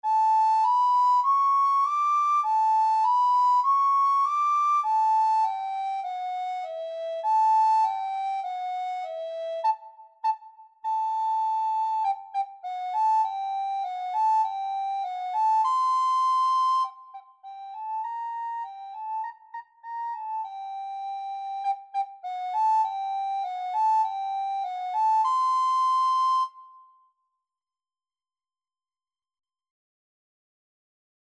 Soprano (Descant) Recorder version
4/4 (View more 4/4 Music)
E6-D7
Beginners Level: Recommended for Beginners
Recorder  (View more Beginners Recorder Music)
Classical (View more Classical Recorder Music)